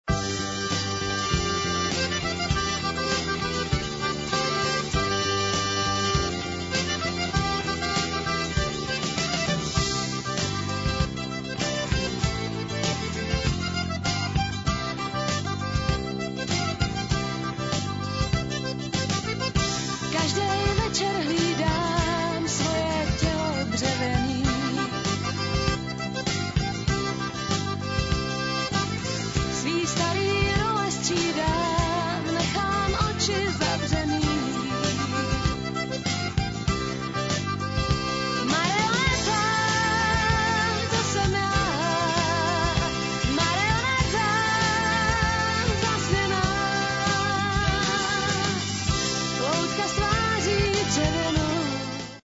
Oblíbená pražská kytarová skupina